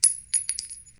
shellDrop2.wav